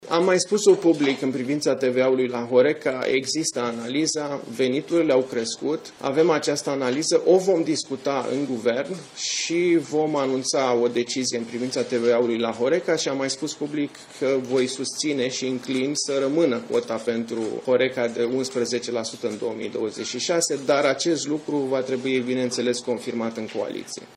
Ministrul Finanțelor, Alexandru Nazare, la o conferință de presa la Palatul Victoria: Înclin să rămână cota pentru HoReCa de 11% în 2026